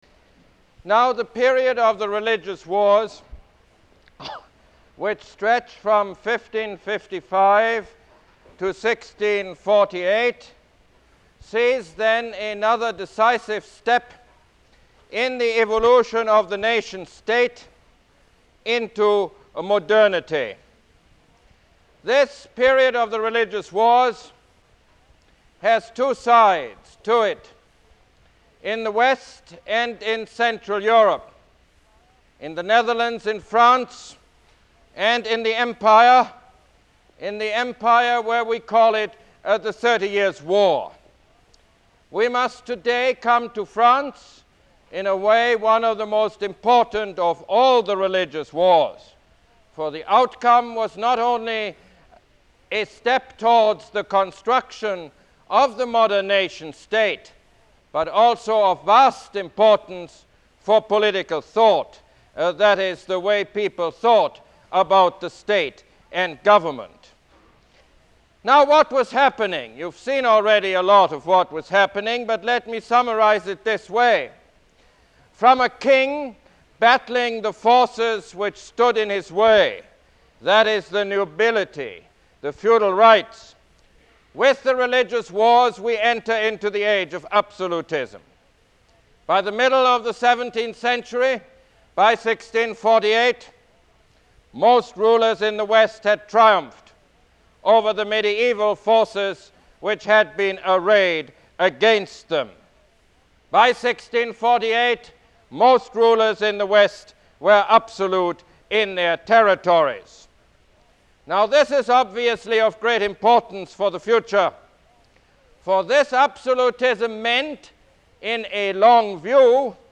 Lecture #10 - The Crisis of the Nation State